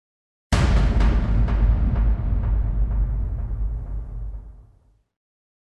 Звуки эха
Кинематографический эффект эхо в детективе когда все встает на свои места